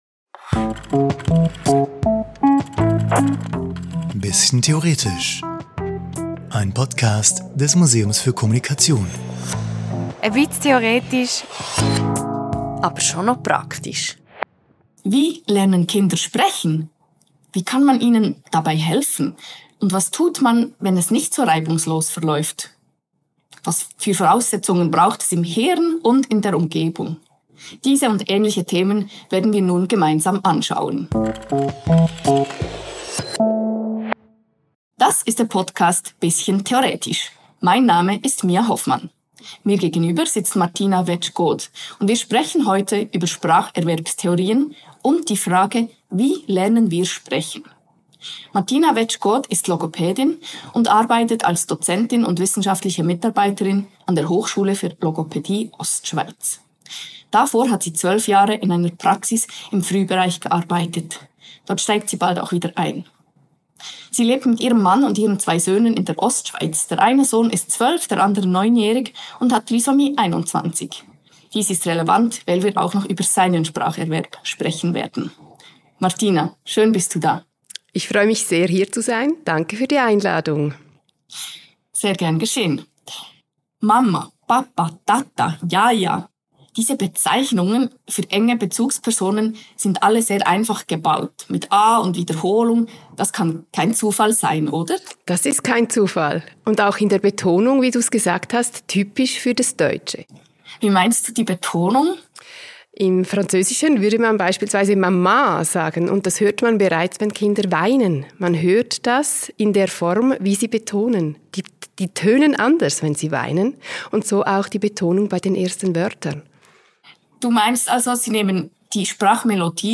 Erwähnungen: «Nativismus» – Noam Chomsky «Kognitivismus» – Jean Piaget Es gab bei der Aufnahme technische Probleme. Diese Folge wurde per Handy aufgenommen und kann deshalb in der Soundqualität von anderen Folgen abweichen.